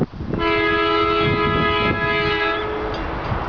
near Toodyay Road